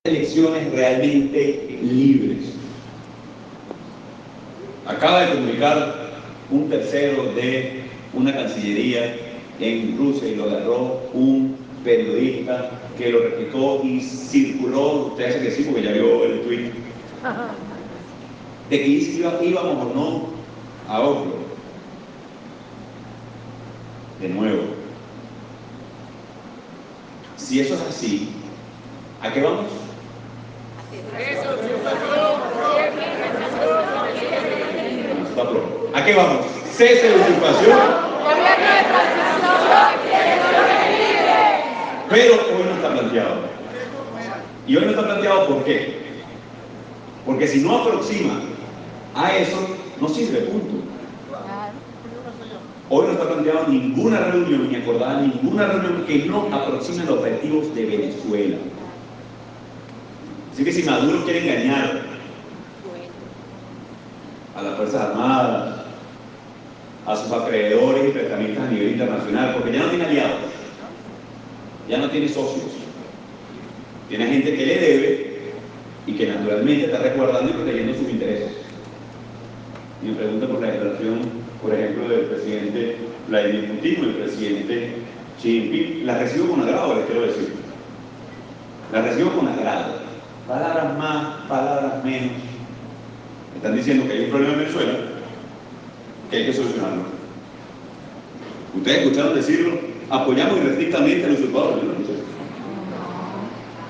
«Hoy no está planteado» y «no está planteado porque si no se aproxima a eso, no sirve. Hoy no está planteada ninguna reunión, ni acordada ninguna reunión», enfatizó Guaidó durante un acto en el estado Carabobo.